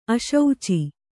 ♪ aśauci